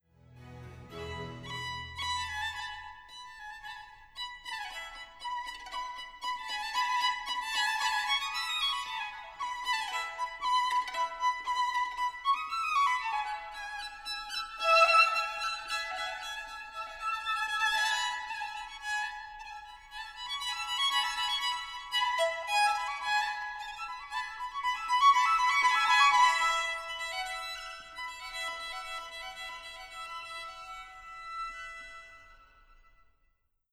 Instrumentation : violon solo, violons 1 et 2, altos, violoncelles et basse continue (clavecin)
7635b-les-oiseaux-version-acoustique.wav